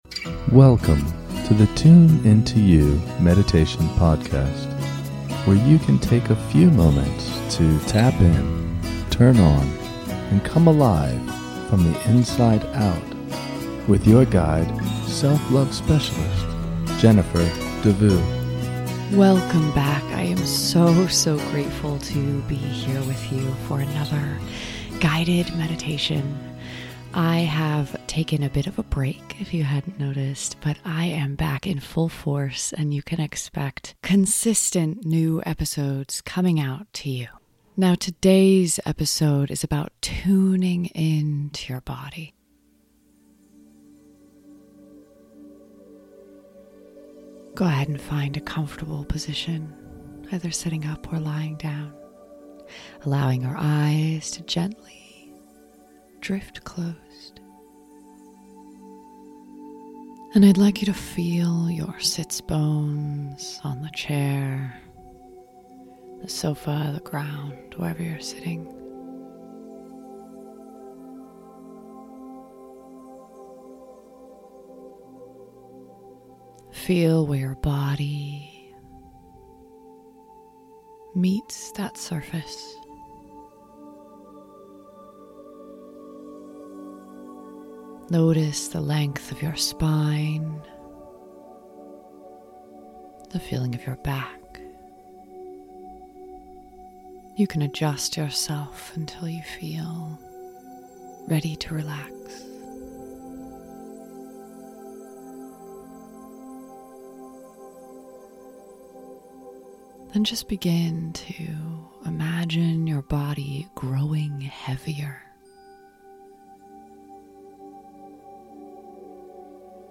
In this short guided meditation, we will tune in to your body and reconnect to the source of life within you and around you in every moment. This meditation is a chance to feel your connection to the divine life force energy of mother earth and father sky.